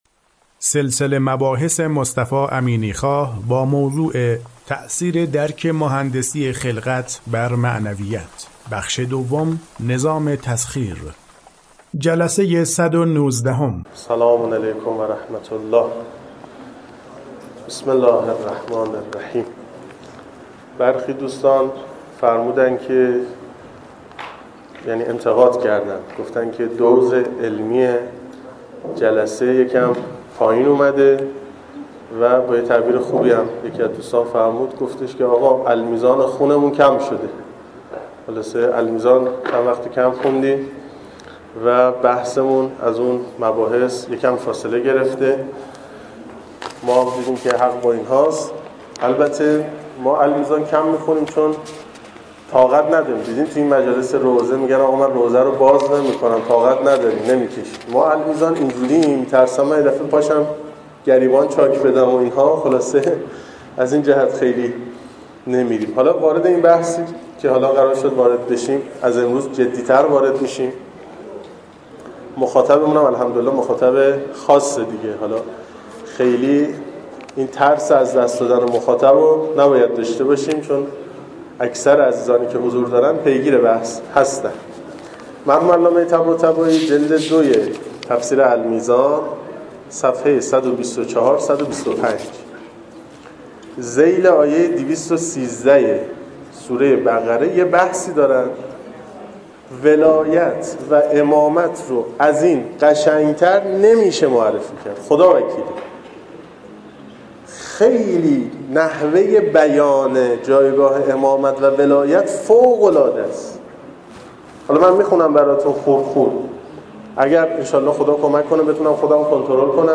سلسله مباحث مهندسی خلقت که در دانشکده مهندسی دانشگاه فردوسی ارائه شده در چند بخش پیگیری می شود که شمای کلی آن بدین شرح است: